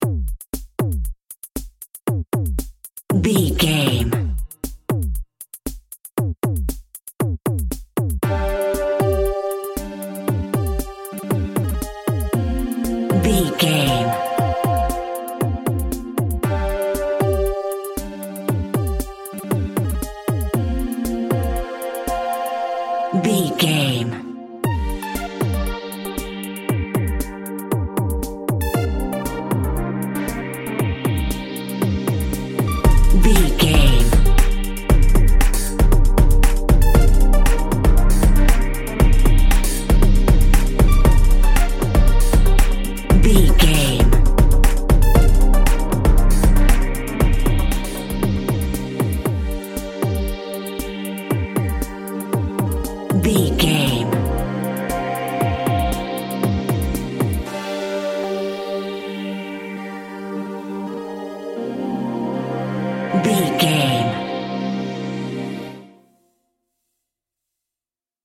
Aeolian/Minor
groovy
uplifting
futuristic
driving
energetic
drums
synthesiser
drum machine
electronic
trance
glitch
synth lead
synth bass